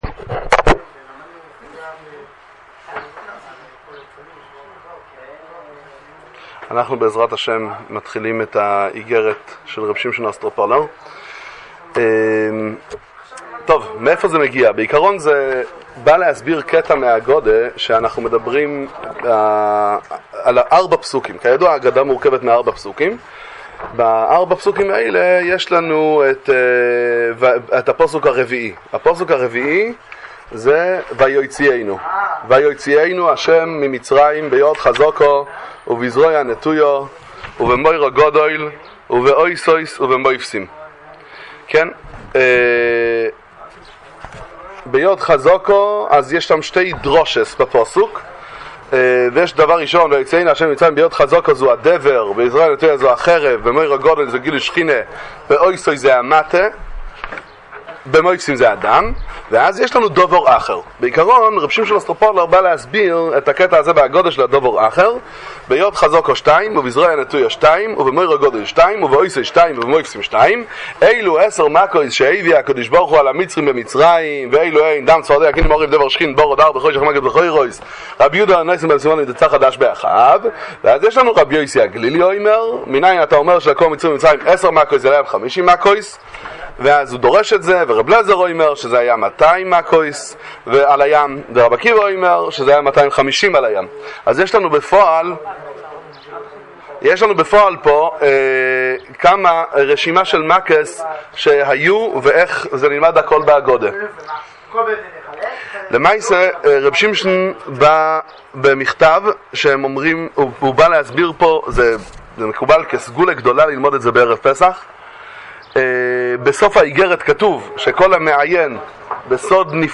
שיעור תורה לפרש את אגרת הסגולה של הקדוש רבי שמשון מאוסטרופולי הי"ד, עם דברי תורה ופירושים
שיעור_על_אגרת_ר_שמשון_מאוסטרופולי.mp3